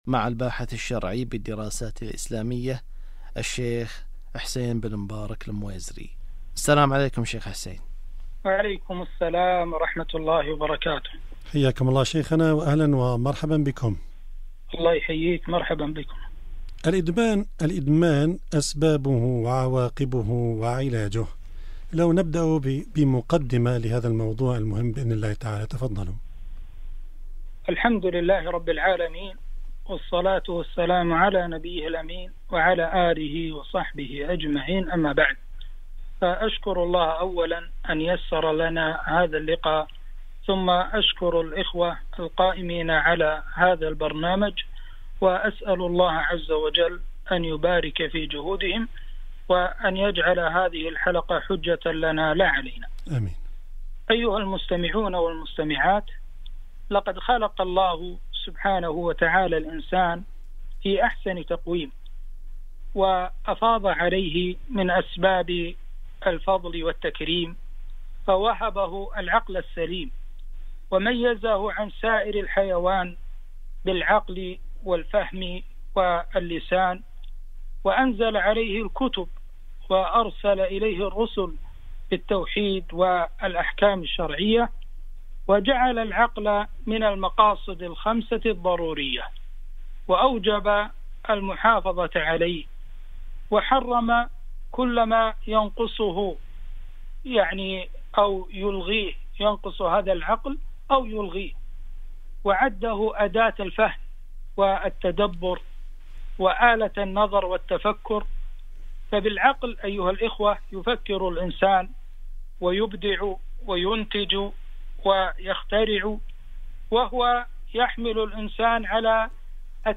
الإدمان أسبابه وعواقبه وعلاجه -لقاء إذاعة القرآن الكريم من دولة الكويت